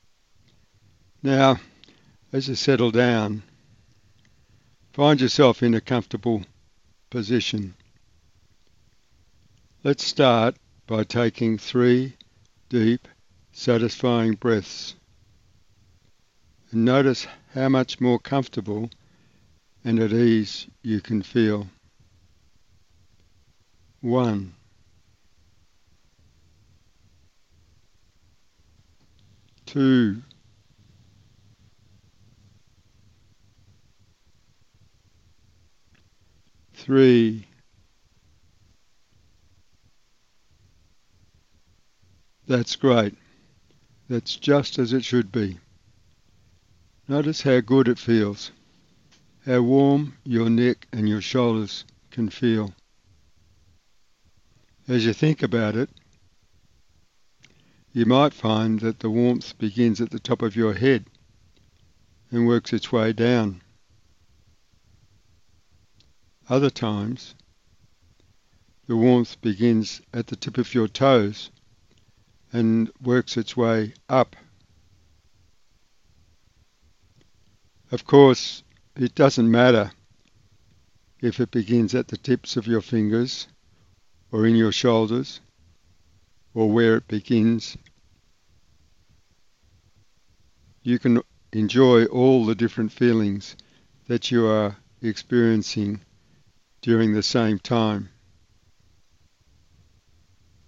"Better Sleep Hypnosis" Audio
These audio hypnosis sessions are designed to help you relax as you go to sleep by focusing your mind on more peaceful and relaxing images.
Better-Sleep-Hypnosis-extract.mp3